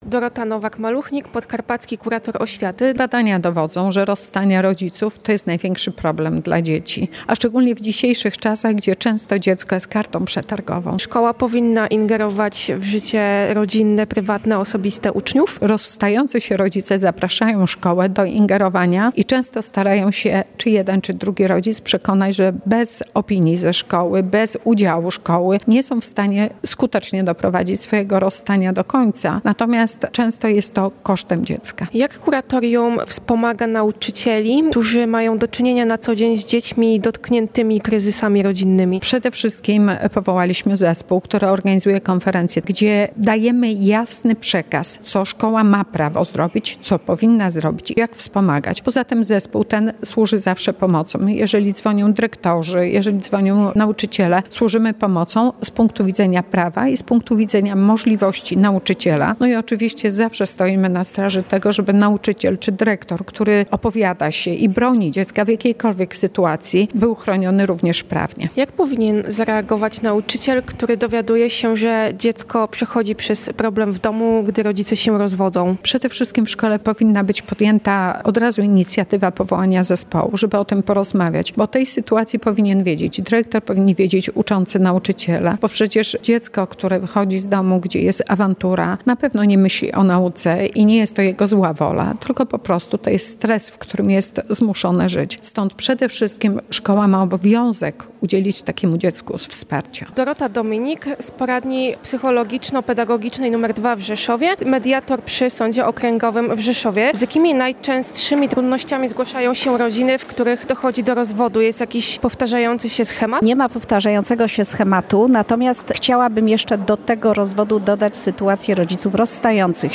O tym, jak skutecznie wspierać uczniów w kryzysie rodzinnym, rozmawiali nauczyciele, pedagodzy i specjaliści podczas forum szkoleniowego zorganizowanego przez Podkarpackie Kuratorium Oświaty w Auli WSPIA Rzeszowskiej Szkoły Wyższej.